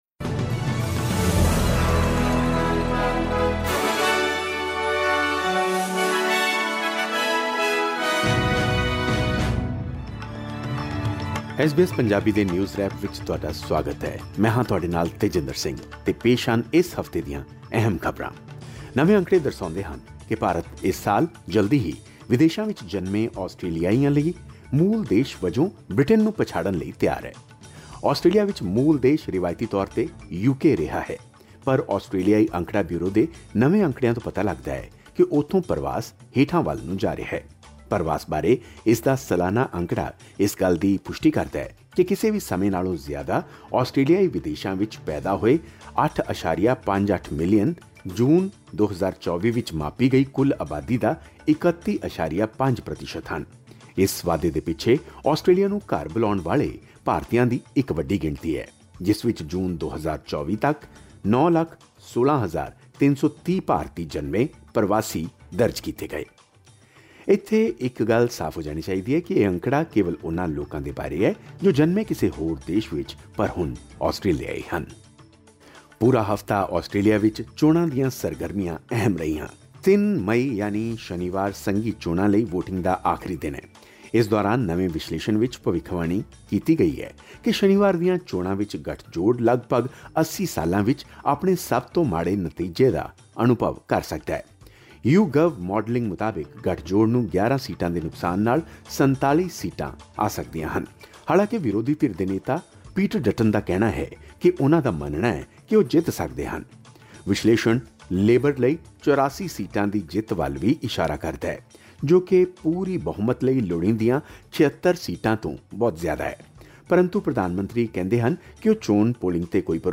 Australian newswrap in Punjabi | SBS Punjabi